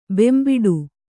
♪ bembiḍu